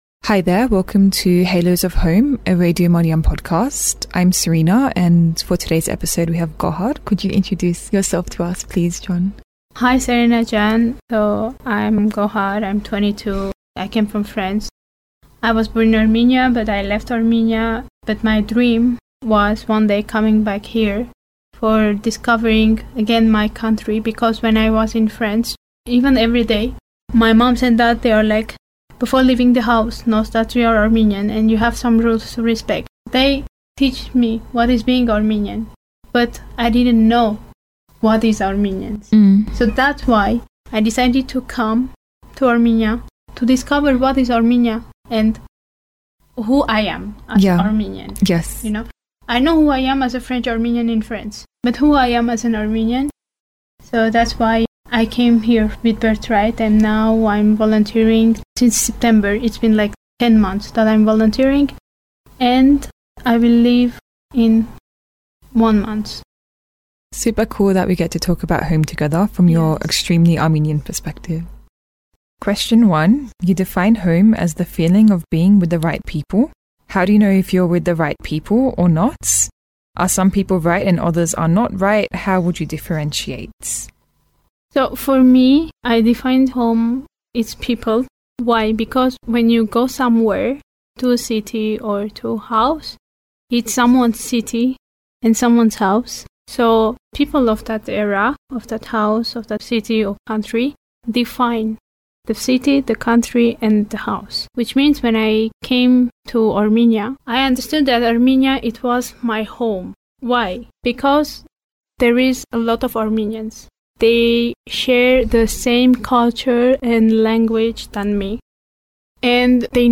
Our conversation touches on hospitality as solidarity, the attitude to the law in Armenia, and my favourite subject discussed was considering the difference between Diasporan Armenians with roots in eastern Armenia, and those with roots elsewhere.